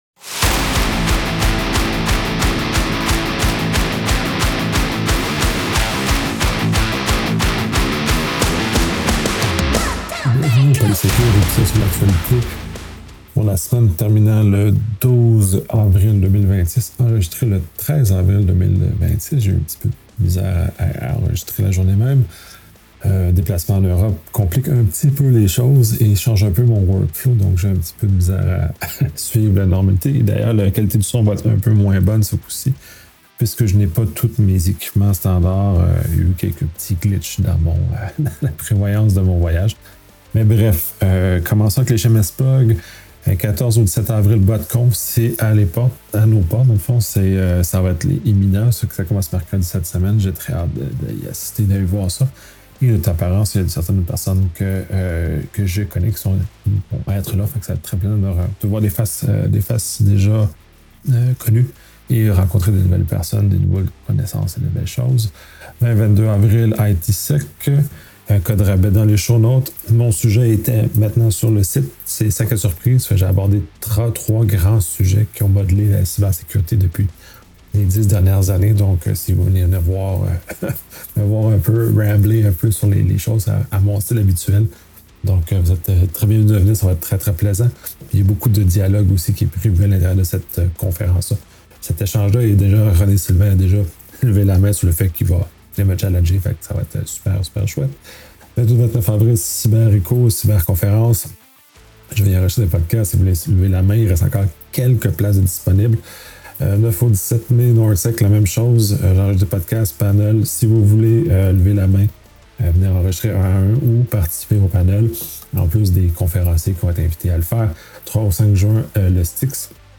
Préambule Je suis en déplacement et je n’ai pas tous mes équipements habituels. Je n’ai pas encore trouvé l’équilibre entre la frugalité des choses que je mets dans ma valise et le maintien de la qualité de l’enregistrement.